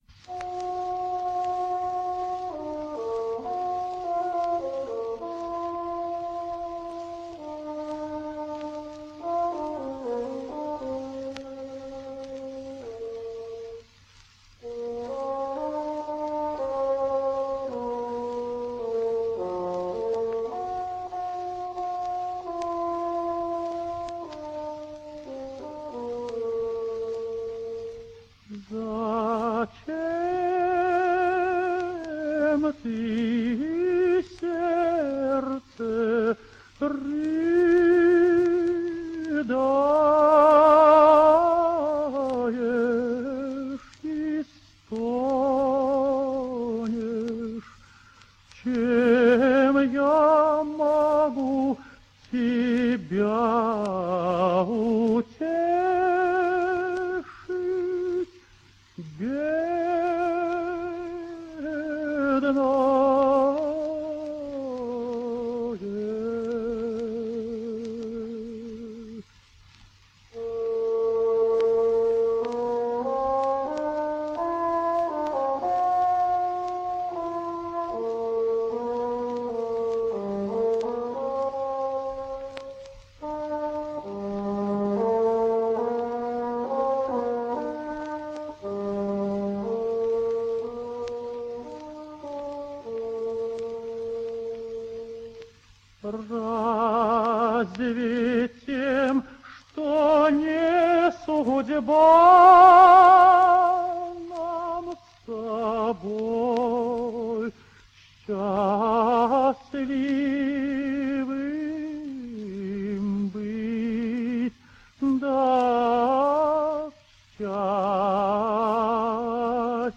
Russian Tenor.
To begin with let’s hear him in that unusual and difficult aria from Mirsovki’s Soroschinsky Fair, where for long periods the singer is unaccompanied with the voice and technique completely exposed.